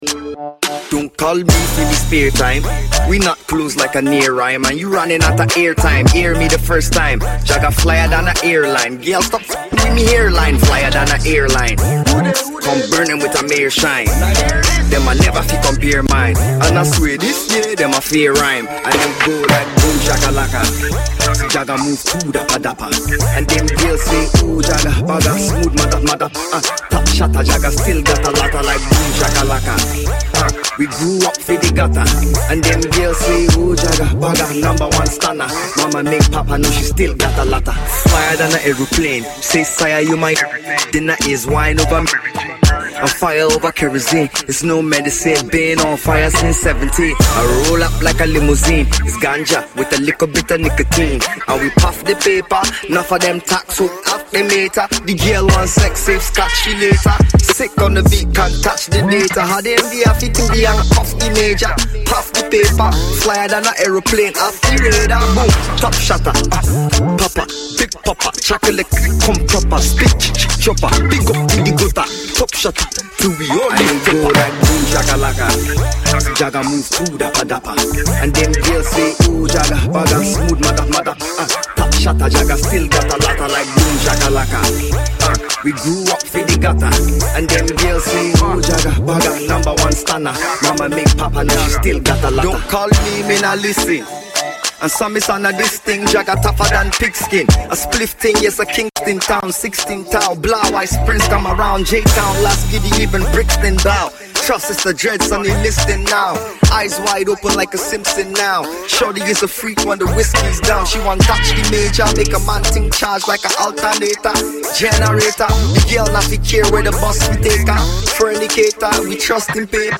feel good record